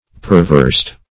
Perversed \Per*versed"\ (p[~e]r*v[~e]rst"), a.
perversed.mp3